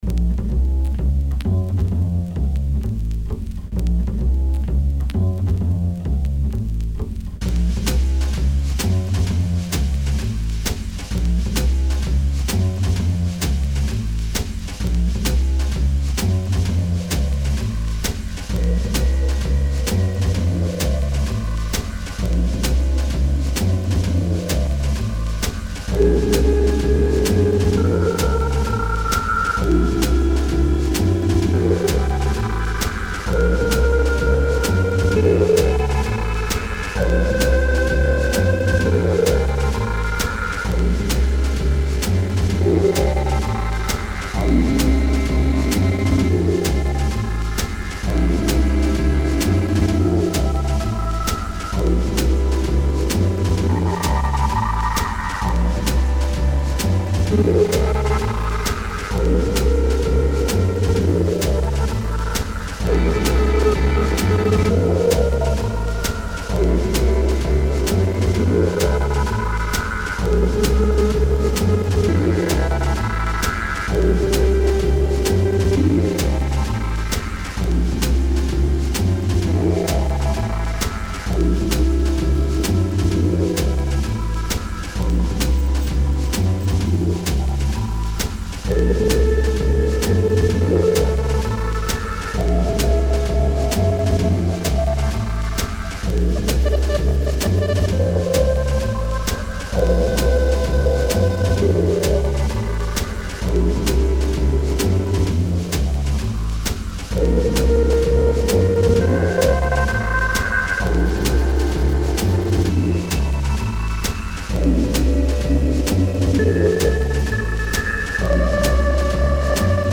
Genre Acid Jazz